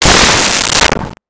Rocket Launcher Sci Fi 01
Rocket Launcher Sci Fi 01.wav